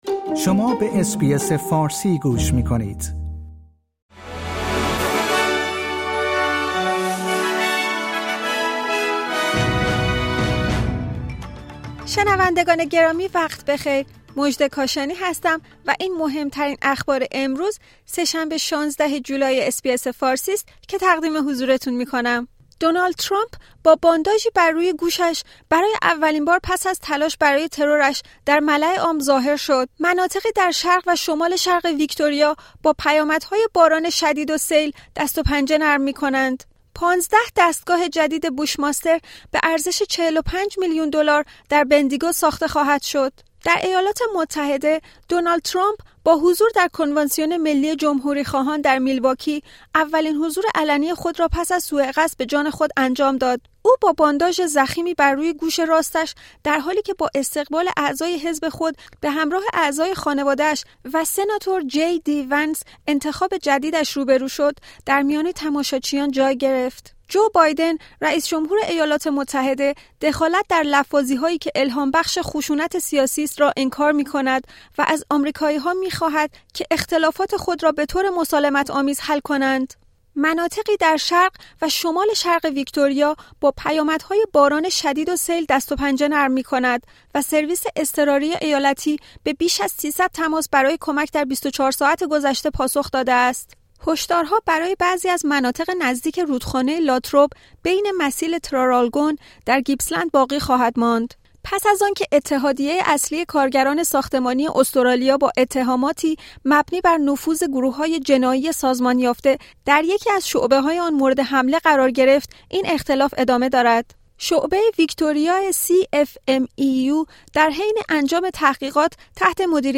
در این پادکست خبری مهمترین اخبار استرالیا در روز سه شنبه ۱۶ جولای ۲۰۲۴ ارائه شده است.